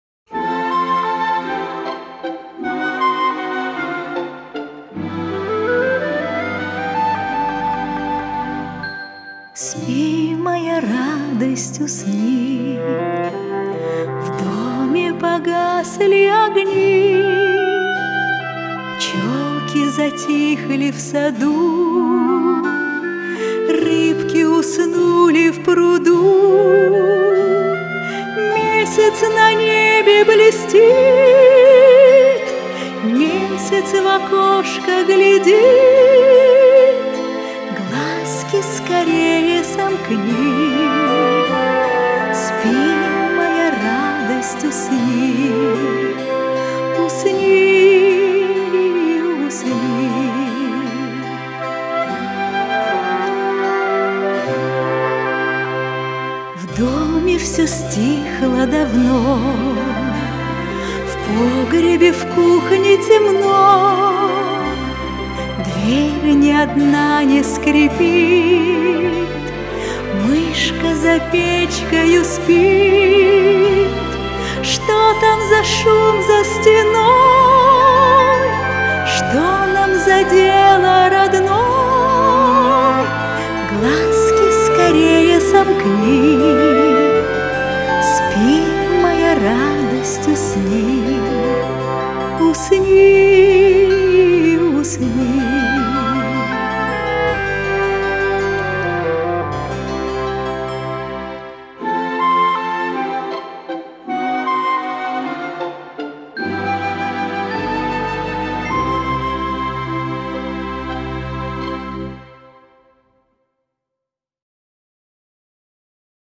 LP vinyl